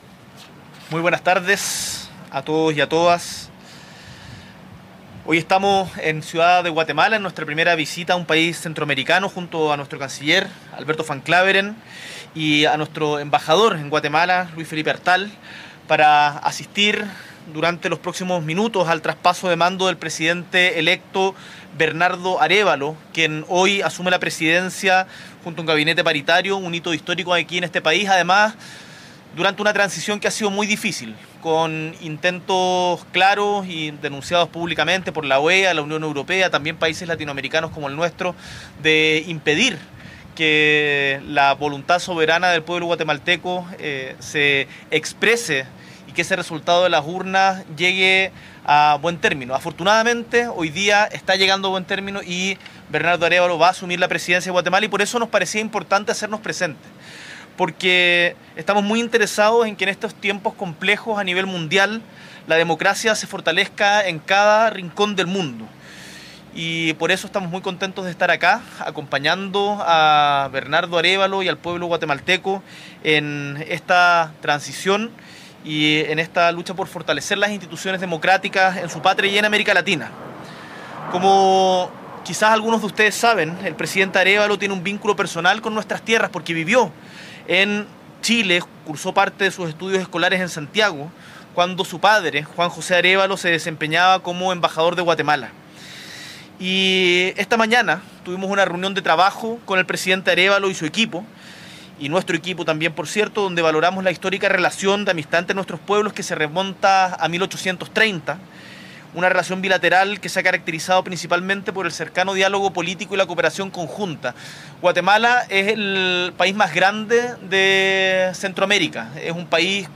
S.E. el Presidente de la República, Gabriel Boric Font, entrega declaraciones desde Guatemala en el marco de la toma de posesión del Presidente Bernardo Arévalo.